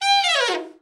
ALT FALL   1.wav